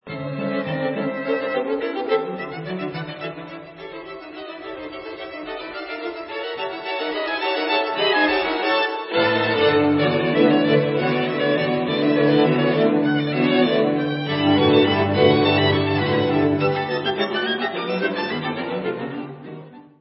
Recording: CHAMBER MUSIC